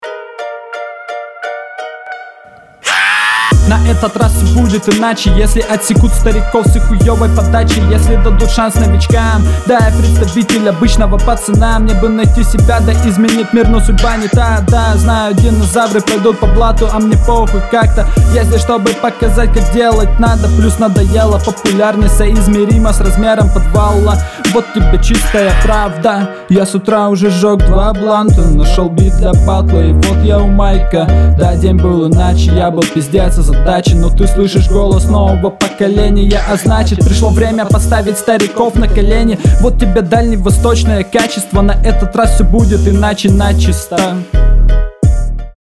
Интонации местами кривоваты.
Читка звучит неуверенно и нечетко вписывается в бит